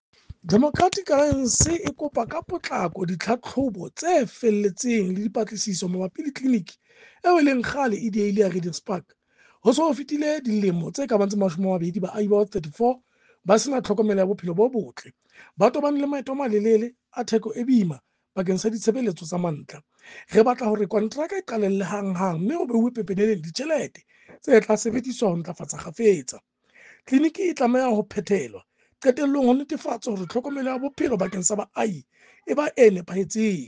Sesotho soundbite by Cllr Kabelo Moreeng.